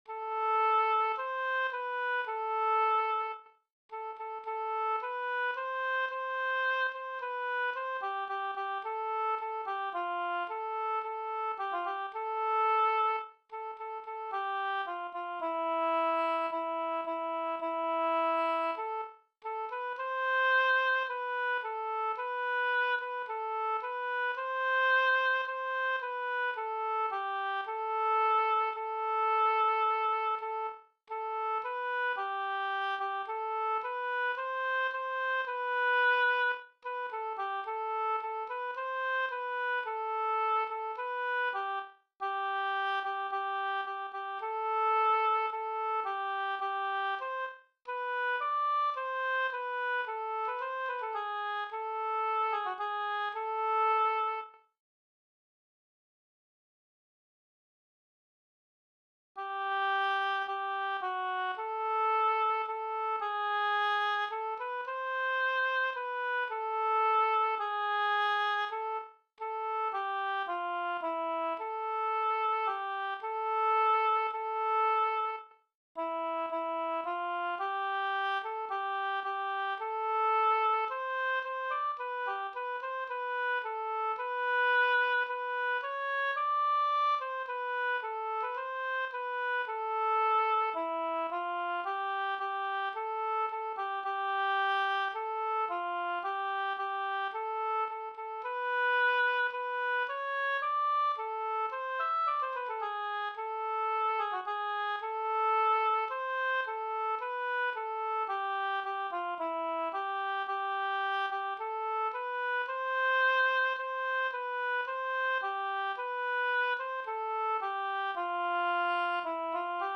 Haleluyah Haleli Psaume 146, Chants religieux, MP3